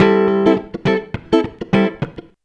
104FUNKY 08.wav